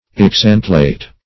Search Result for " exantlate" : The Collaborative International Dictionary of English v.0.48: Exantlate \Ex*ant"late\, v. t. [L. exantlatus, p. p. of exantlare, exanclare, to endure.] To exhaust or wear out.